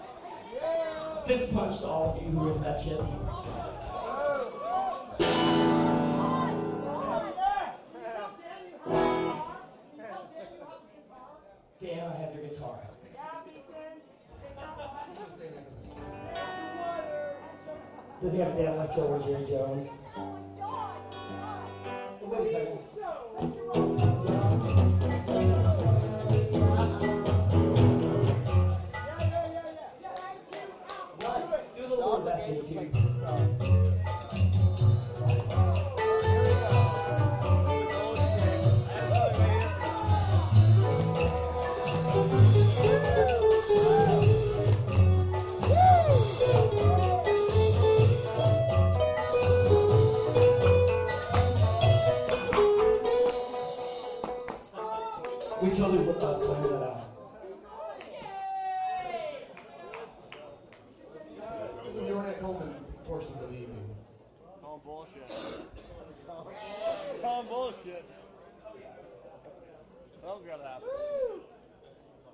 64kbit Mono MP3s